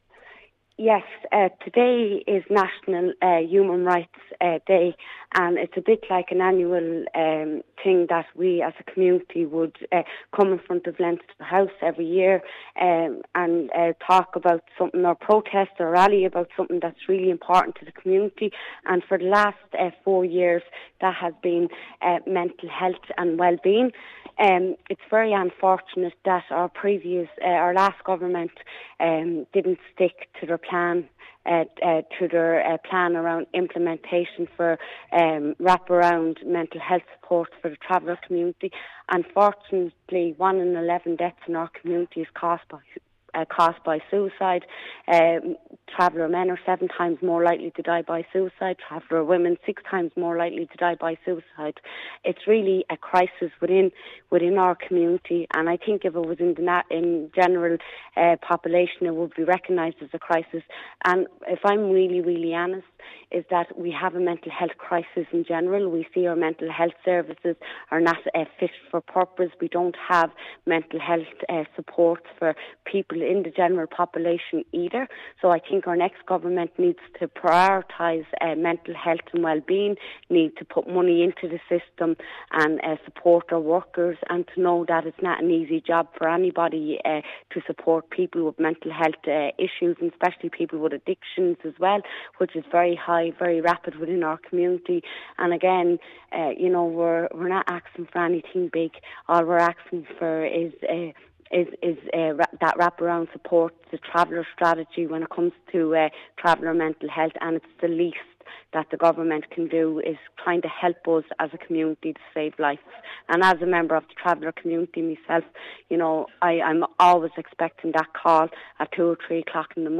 Senator Eileen Flynn says the new Government must work together to help the growing problem.